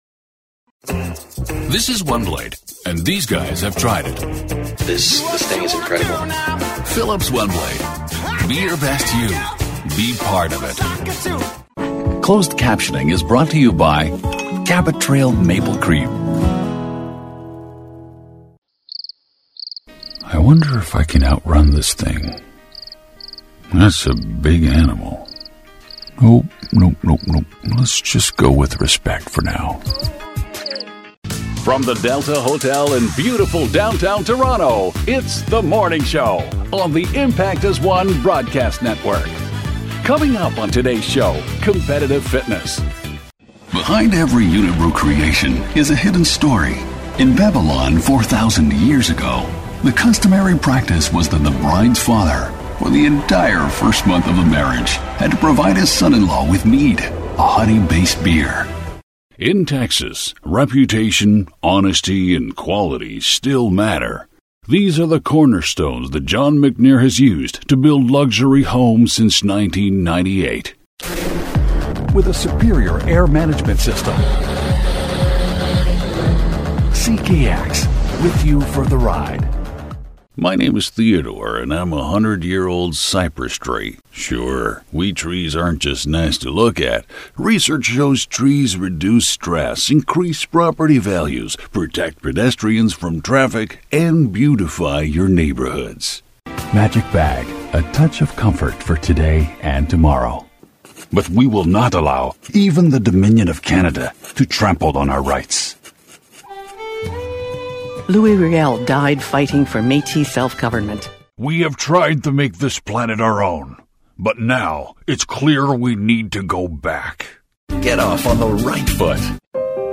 Commercial demo- English, Deep, resonant, credible and passionate